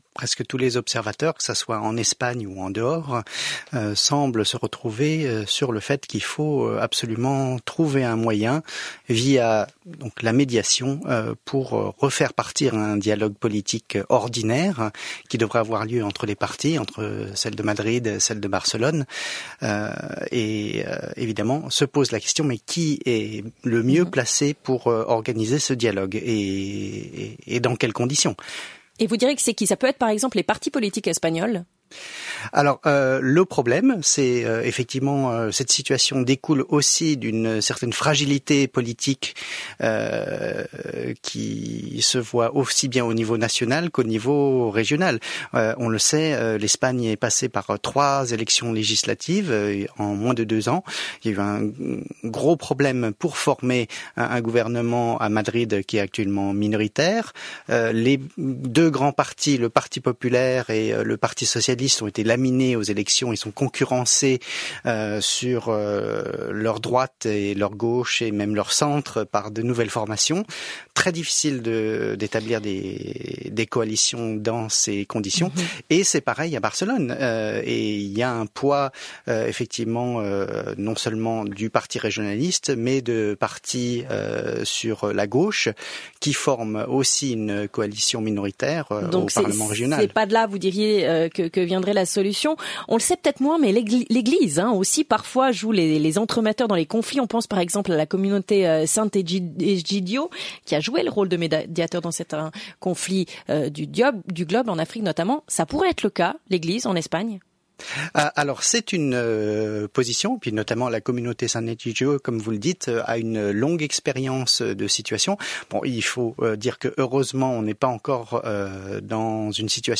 Son radio